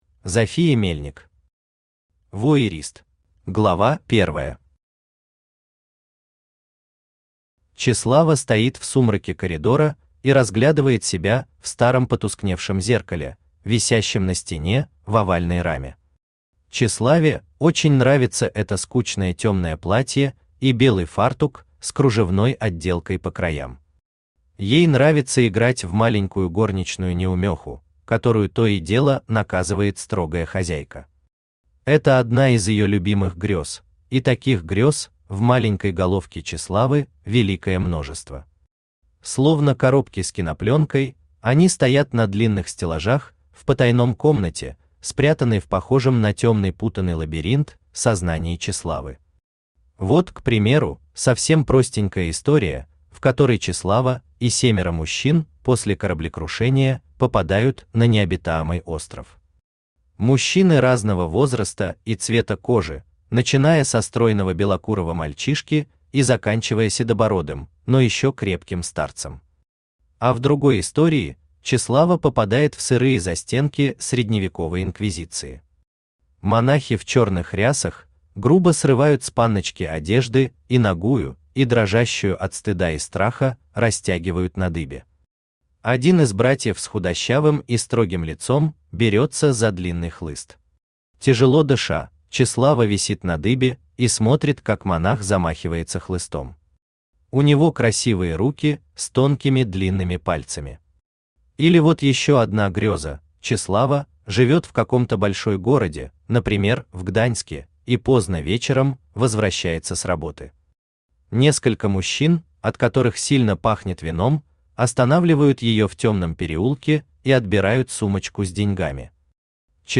Aудиокнига Вуайерист Автор Зофия Мельник Читает аудиокнигу Авточтец ЛитРес.